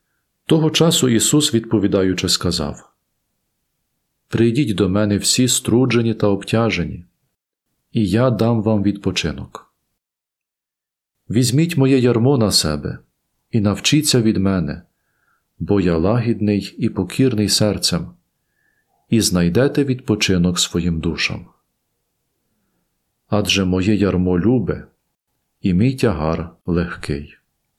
Євангеліє